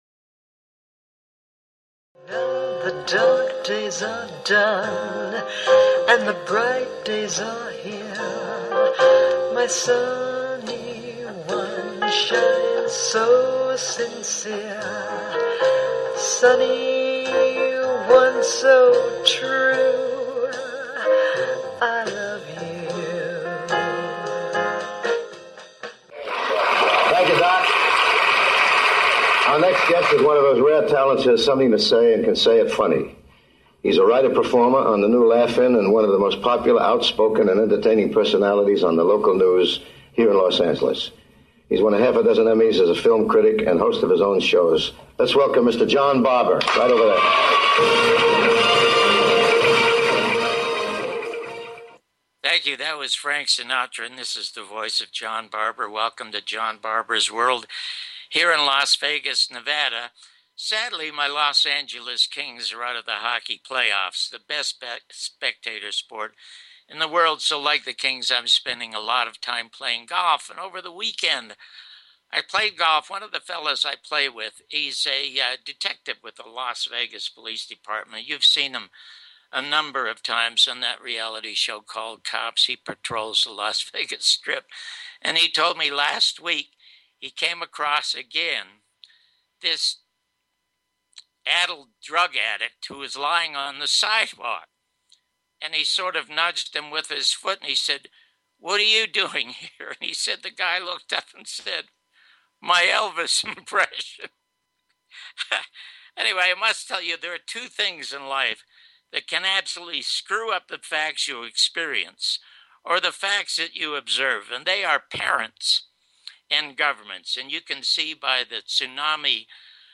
Guest, Kitty Kelley
John Barbour's World with John Barbour and special guest Kitty Kelley